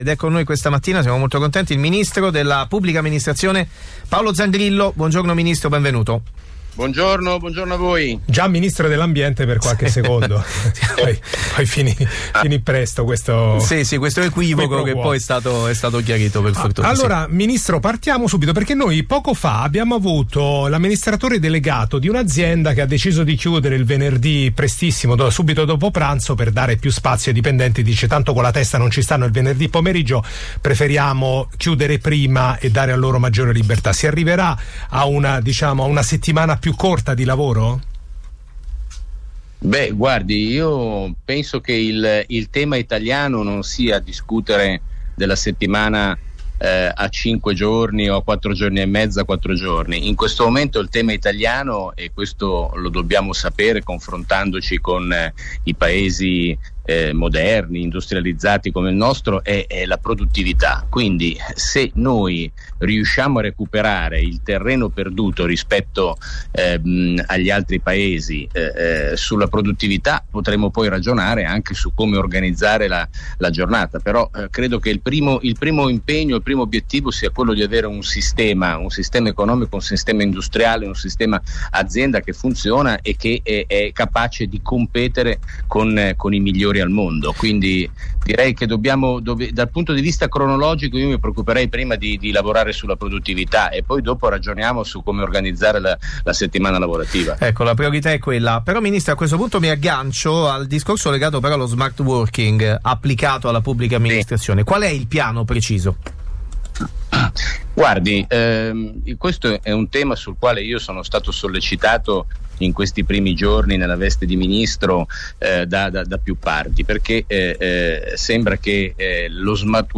Il Ministro è intervenuto alla trasmissione "The Breakfast Club"
Intervista Radio Capital 8.11.2022
Questa mattina il ministro per la Pubblica amministrazione, Paolo Zangrillo, ospite a "The Breakfast Club" su Radio Capital.